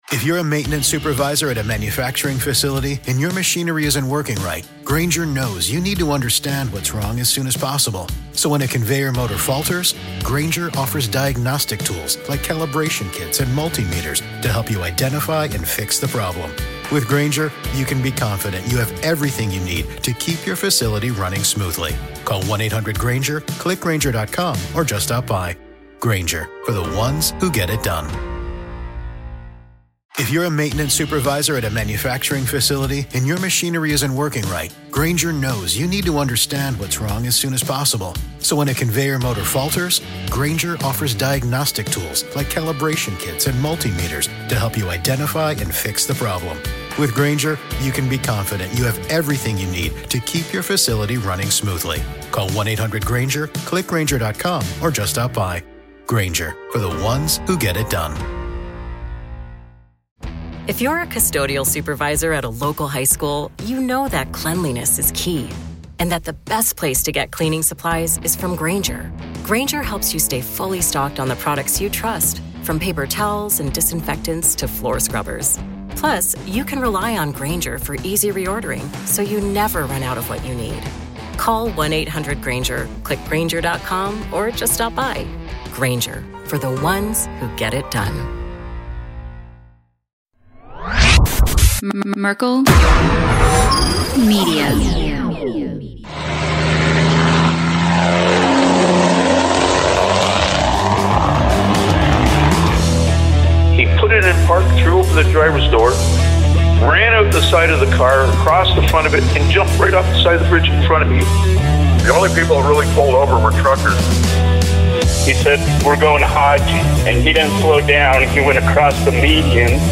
This was an episode full of random rabbit holes, hilarious laughs, and great conversation.